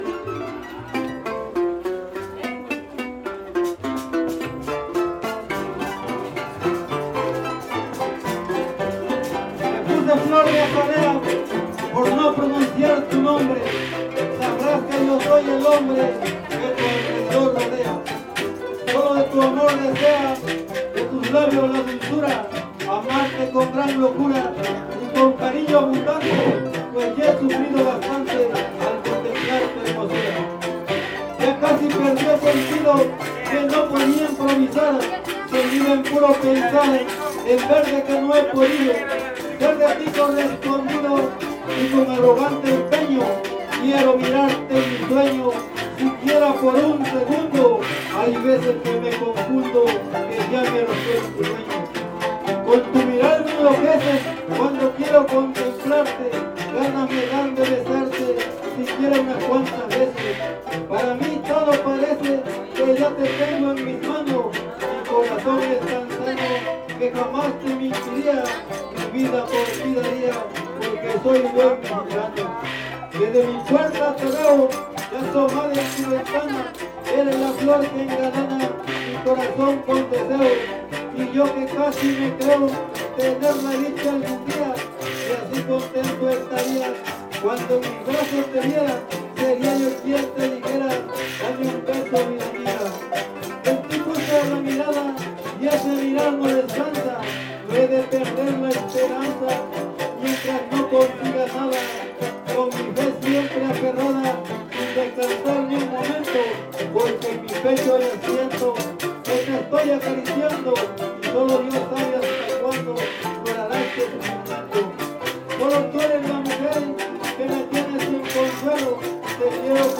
Fiesta Patronal de San Antonio de Padua